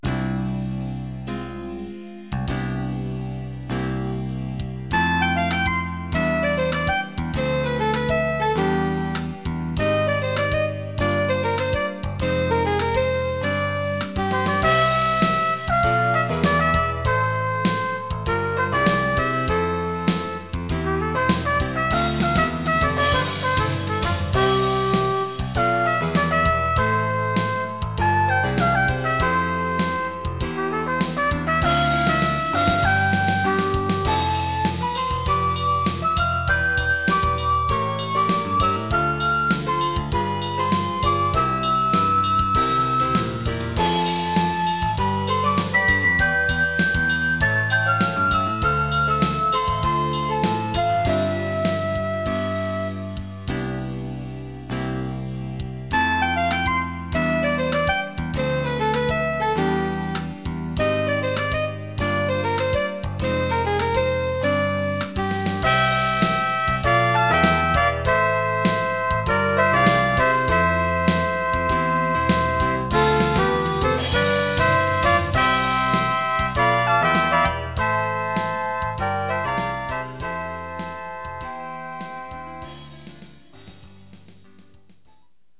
rain2s.mp3